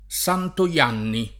santo [S#nto] agg. e s. m. — elis.